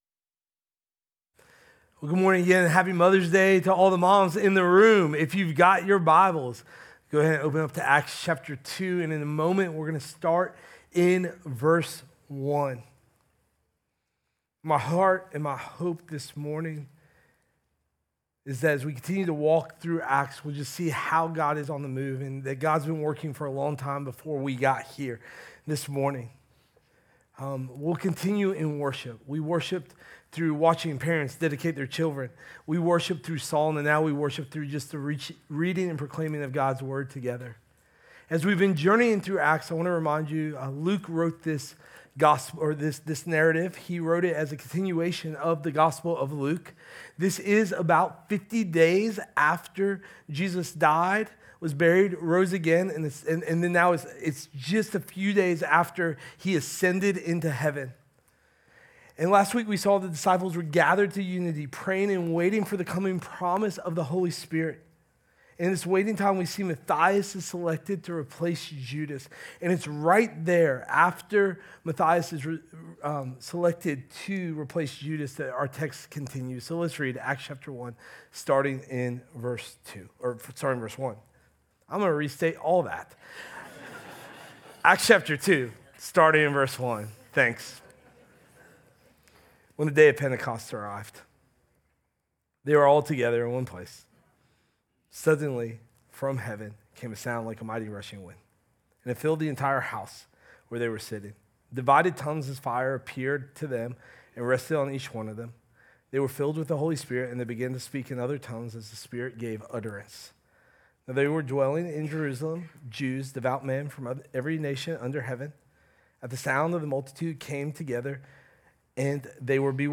Sermon Audio Sermon Notes…